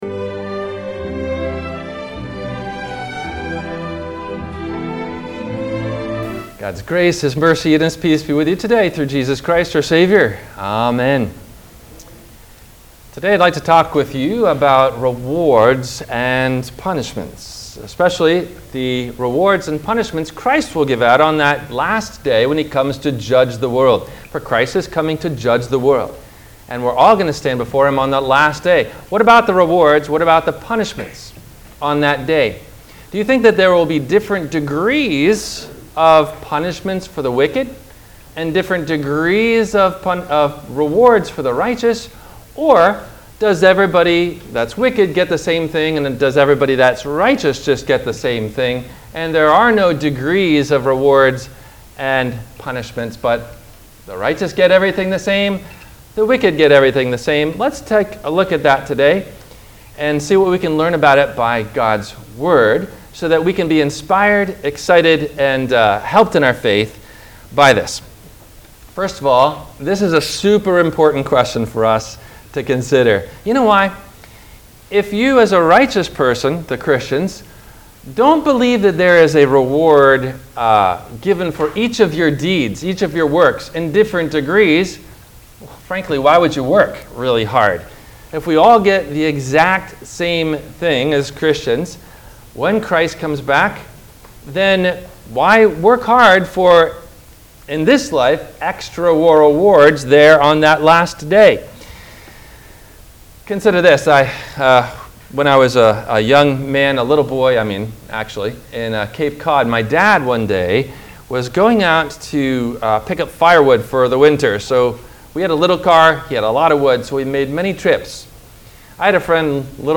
– WMIE Radio Sermon – September 02 2024 - Christ Lutheran Cape Canaveral
No Questions asked before the Radio Message.